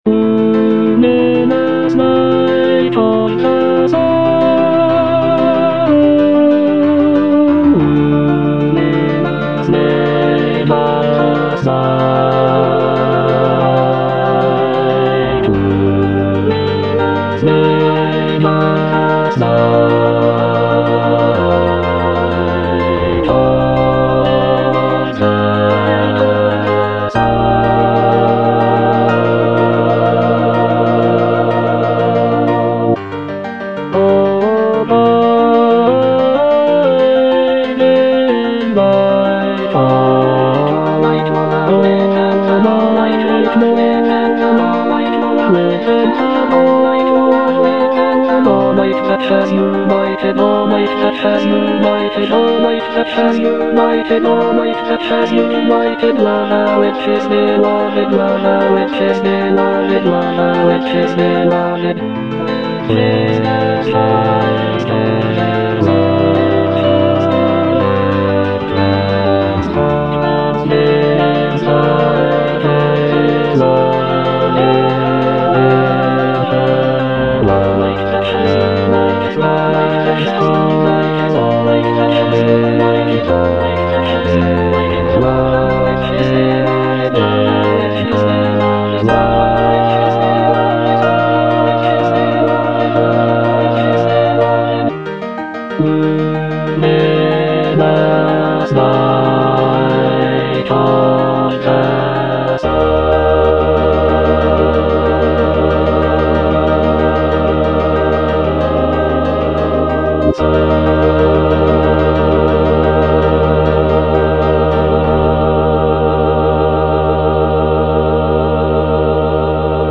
bass II) (Emphasised voice and other voices) Ads stop